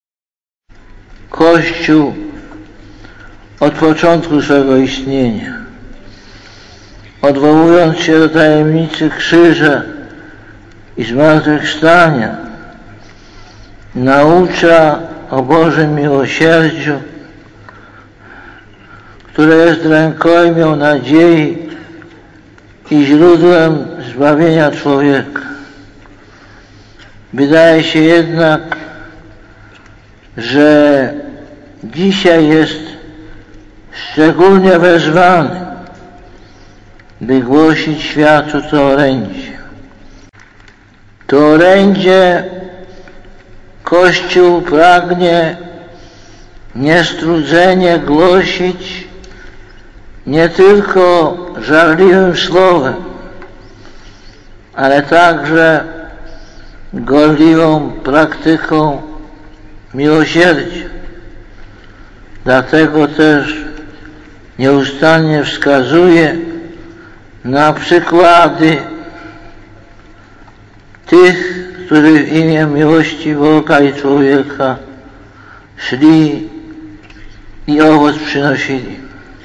Głos Papieża: (